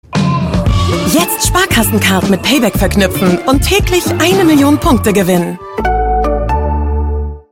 markant, sehr variabel
Mittel minus (25-45)
Norddeutsch
Commercial (Werbung), Off